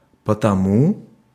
Ääntäminen
потому́